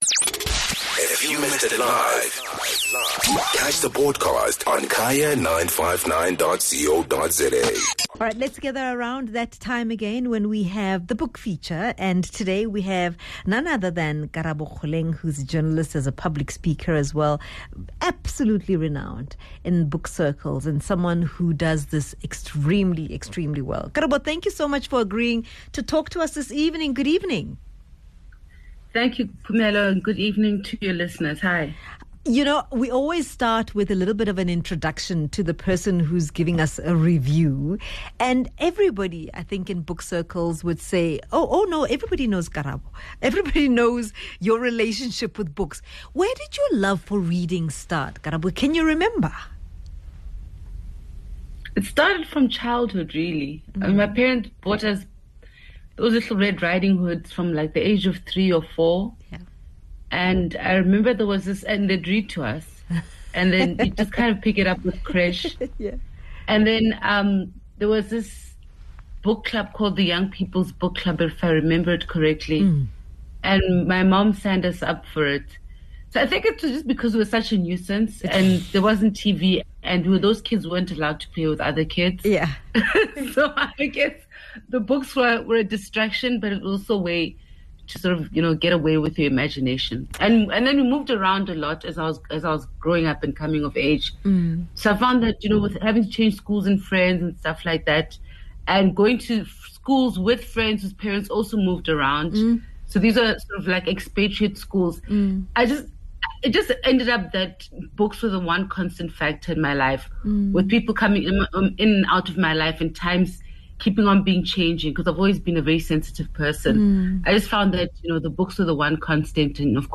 BOOK REVIEW